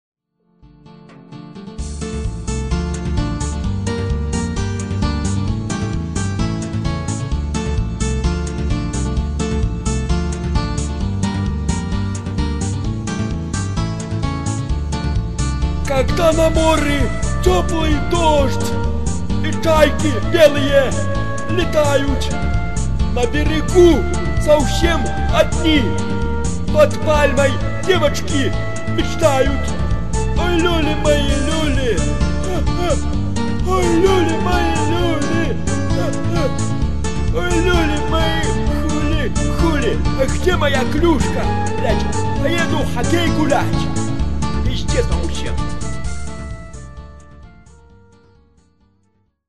Цыганские народные песни.